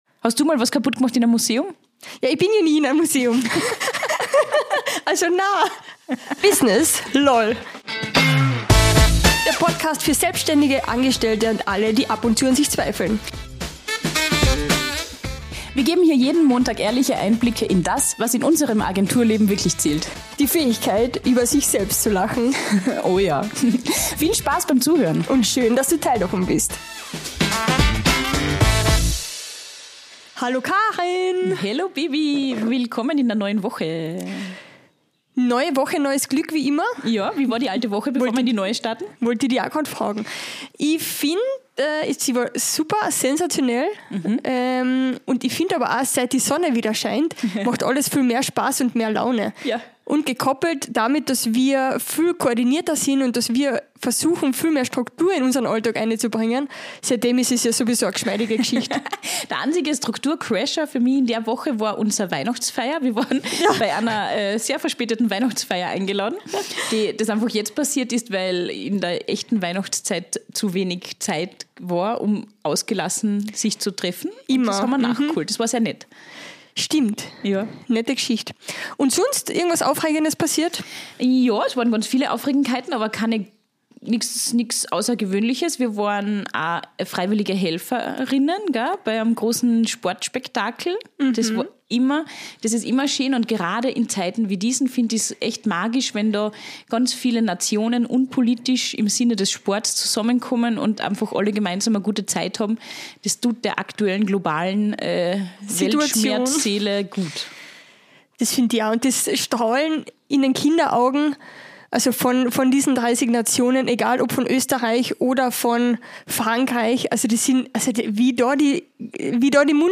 Viele aus unserer nicht repräsentativen Straßenumfrage haben behauptet, Intelligenz habe etwas mit Allgemeinwissen oder Hausverstand zu tun.